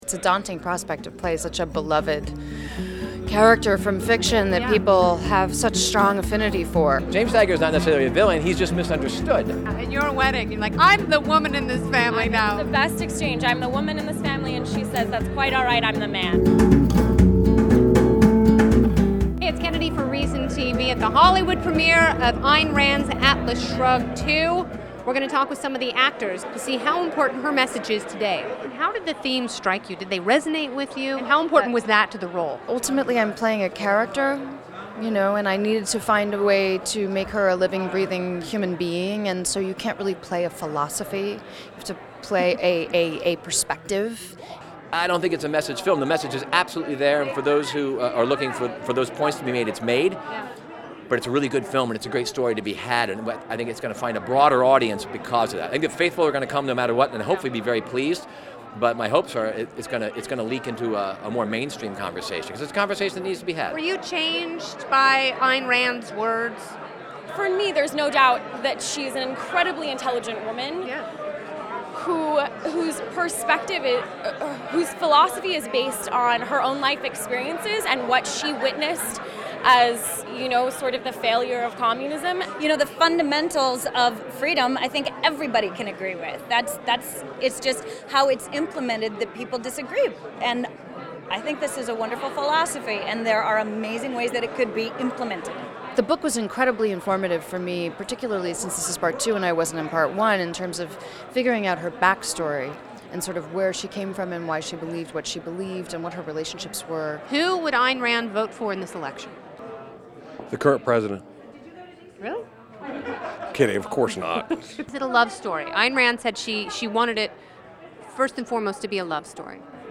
ReasonTV correspondent Kennedy spoke with Mathis and other cast members at the film's Hollywood Premiere on Oct. 5 to find out how they connected with their characters and the themes portrayed in the movie.